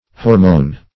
Hormone \Hor"mone\ (h[^o]r"m[=o]n), n. [From Gr.